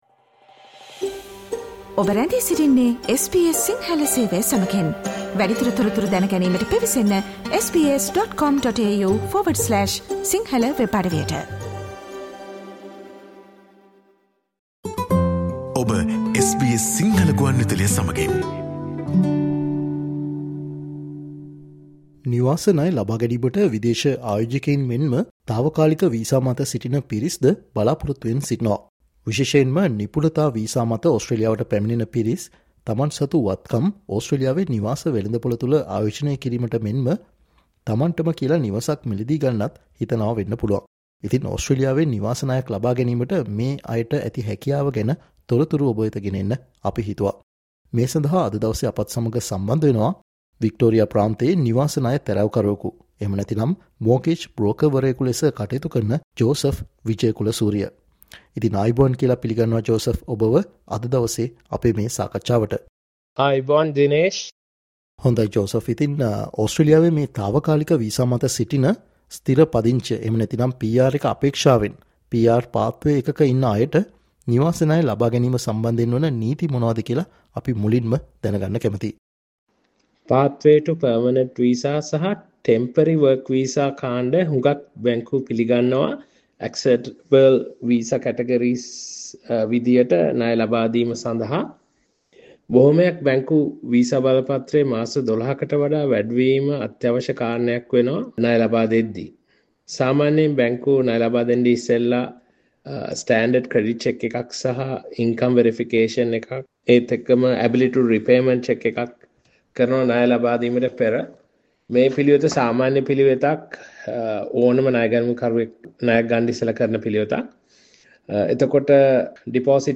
තාවකාලික වීසා එකක ඉන්න අය, විශේෂයෙන්ම ඕස්ට්‍රේලියාවේ ස්ථිර පදිංචිය අපේක්ෂාවෙන් ඉන්න අය ඕස්ට්‍රේලියාවේ නිවසක් මිලදීගන්න හෝ නිවාස වෙළඳපොලේ ආයෝජනය කරන්න හිතනවානම් එහිදී සැලකිලිමත් විය යුතු කාරනා ගැන SBS සිංහල සේවය සිදුකල සාකච්චාවට සවන් දෙන්න